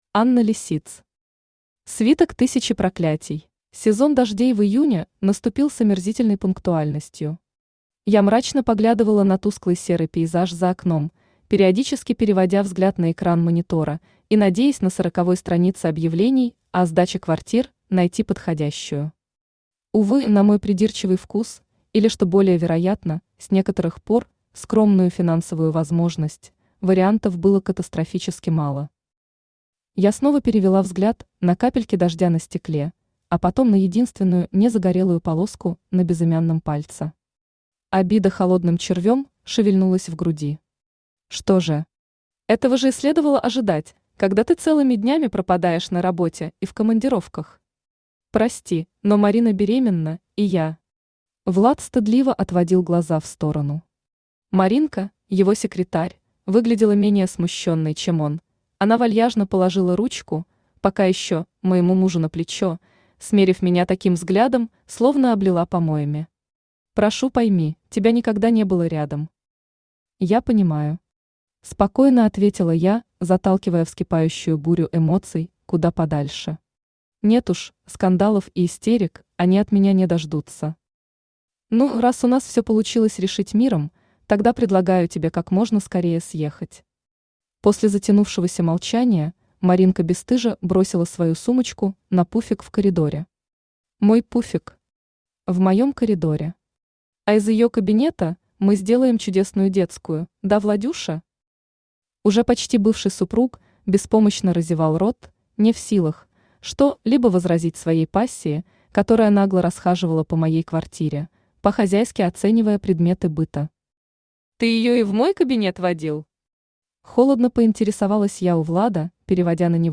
Аудиокнига Свиток тысячи проклятий | Библиотека аудиокниг
Aудиокнига Свиток тысячи проклятий Автор Анна Андреевна Лисиц Читает аудиокнигу Авточтец ЛитРес.